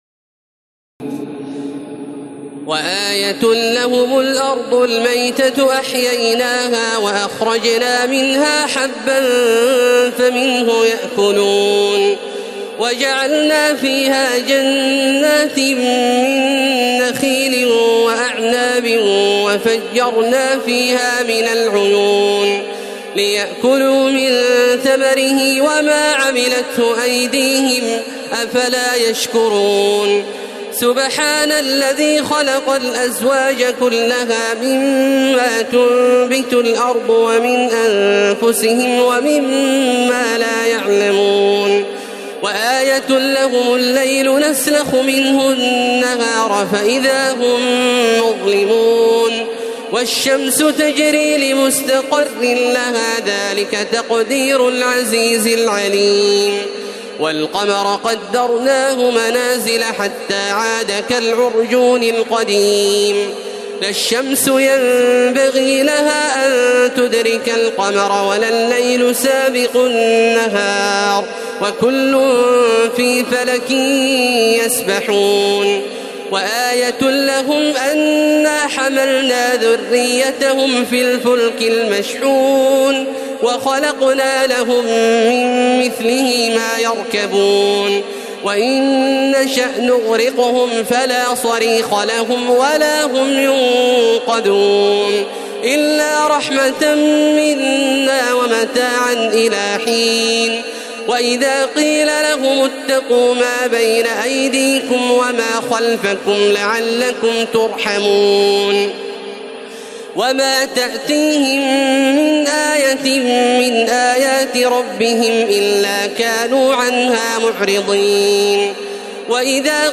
تراويح ليلة 22 رمضان 1432هـ من سور يس (33-83) والصافات(1-138) Taraweeh 22 st night Ramadan 1432H from Surah Yaseen and As-Saaffaat > تراويح الحرم المكي عام 1432 🕋 > التراويح - تلاوات الحرمين